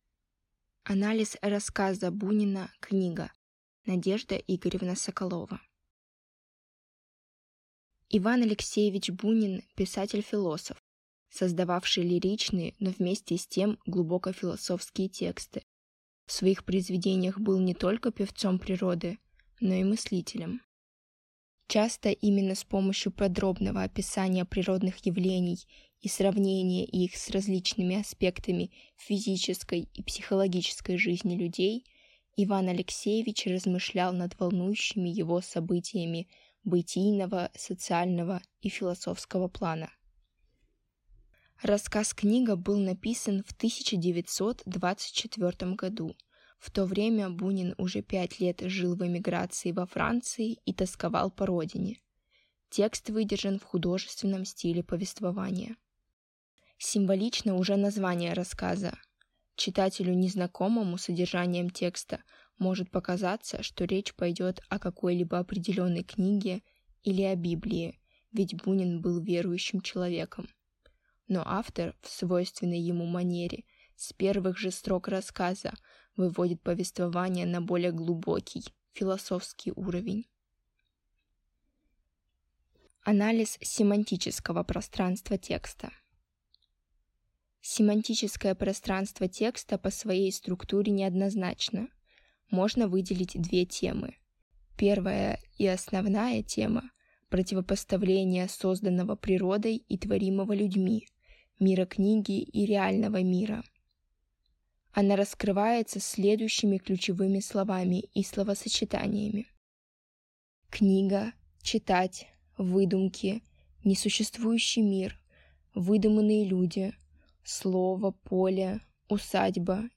Аудиокнига Анализ рассказа Бунина «Книга» | Библиотека аудиокниг